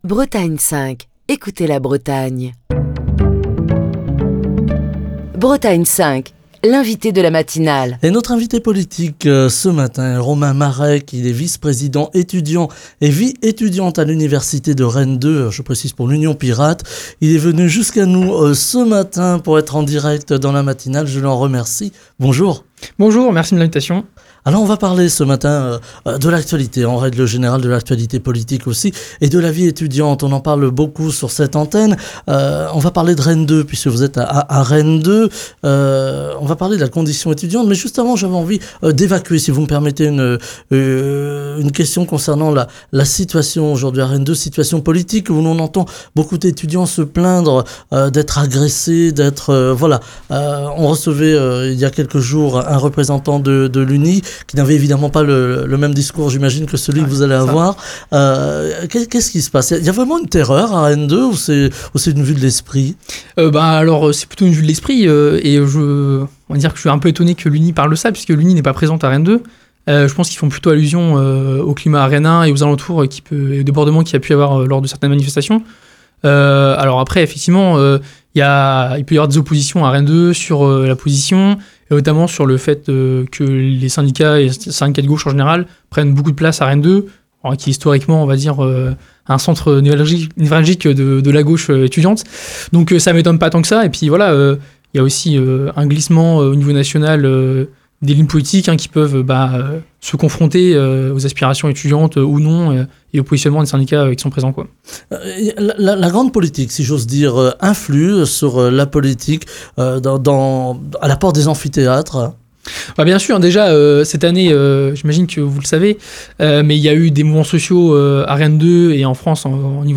était l'invité politique de la matinale de Bretagne 5.